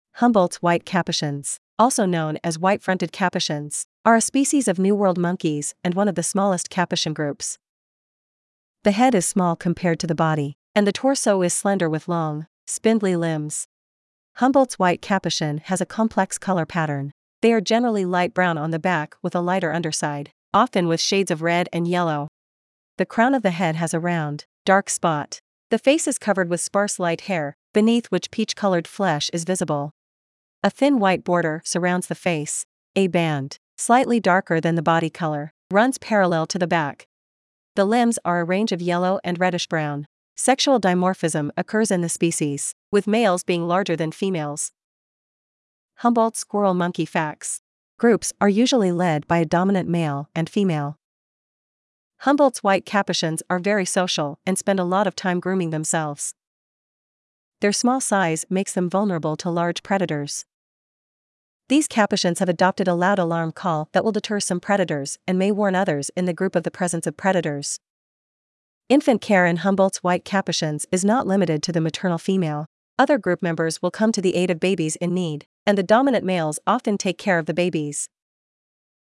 • These capuchins have adopted a loud alarm call that will deter some predators and may warn others in the group of the presence of predators.
Humboldts-white-capuchins.mp3